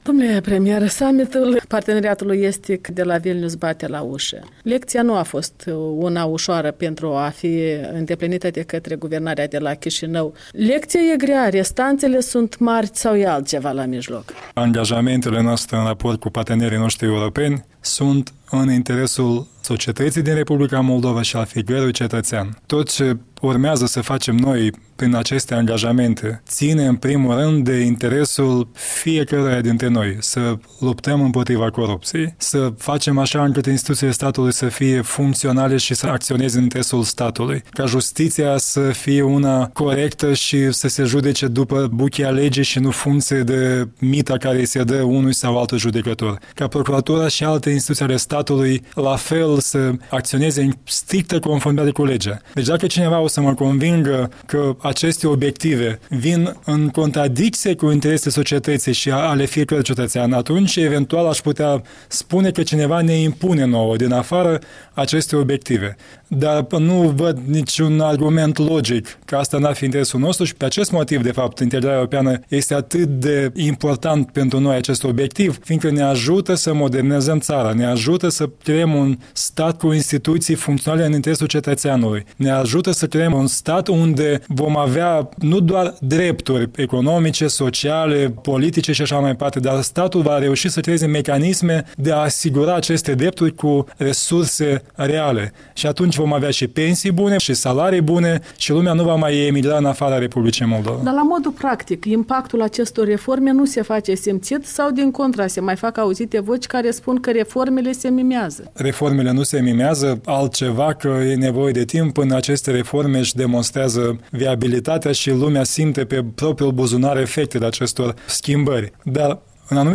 Interviu cu primul ministru Iurie Leancă